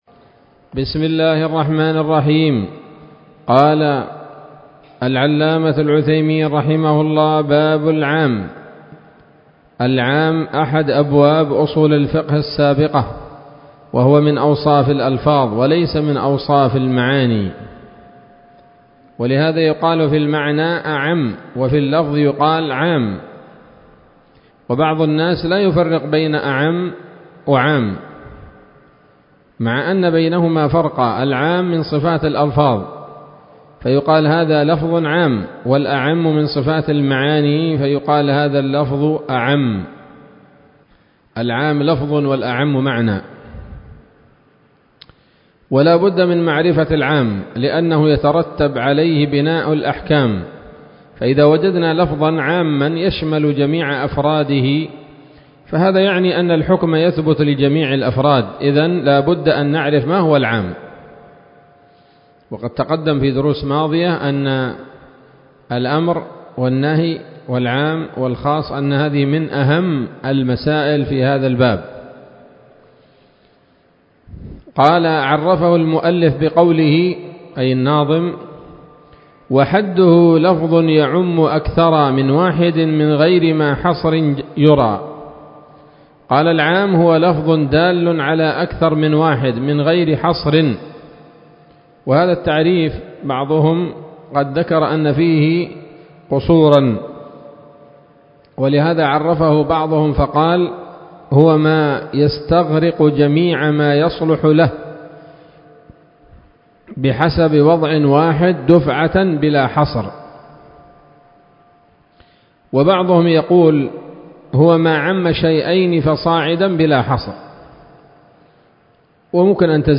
الدرس السابع والثلاثون من شرح نظم الورقات للعلامة العثيمين رحمه الله تعالى